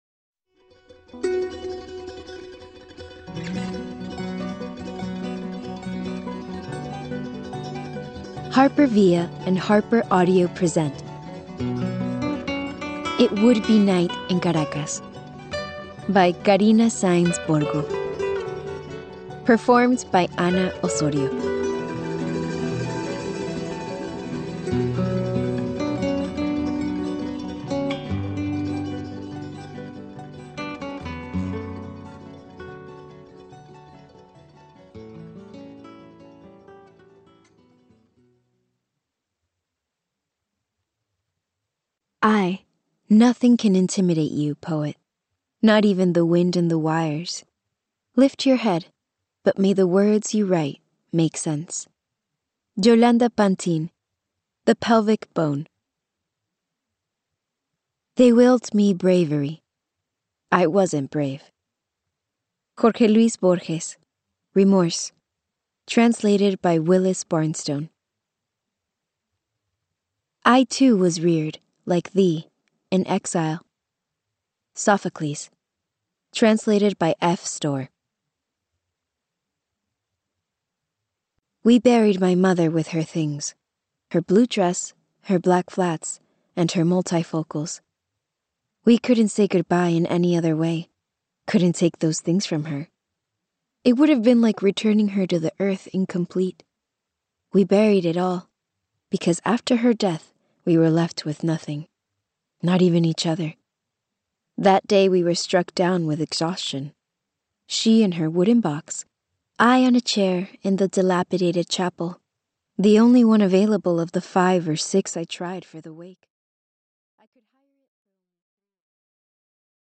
OverDrive MP3 Audiobook, OverDrive Listen
Unabridged